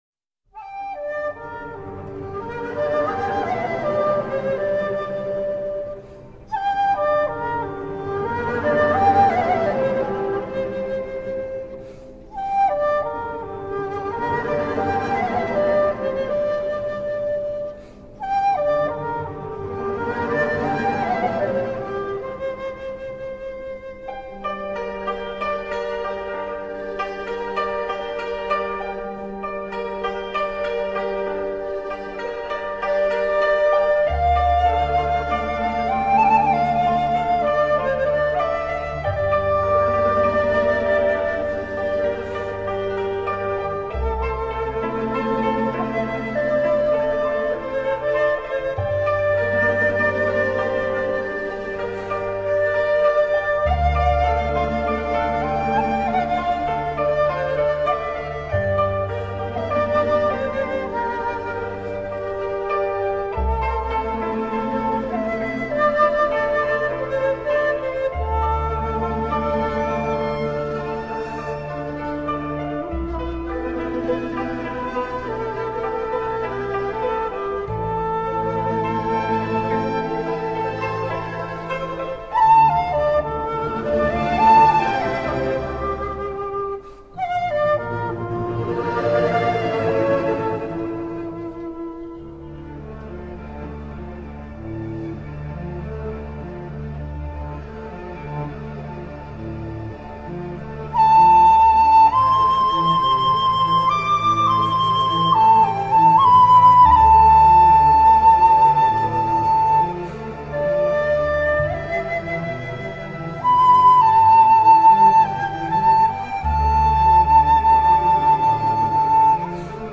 箫